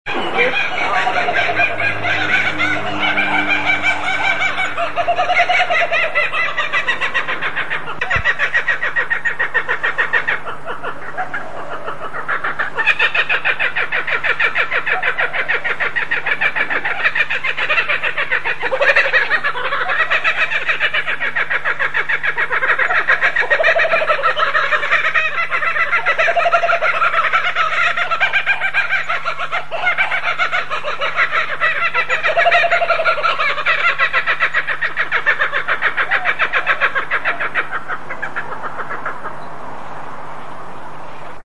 Kookaburra In The Tree 1 Mp 3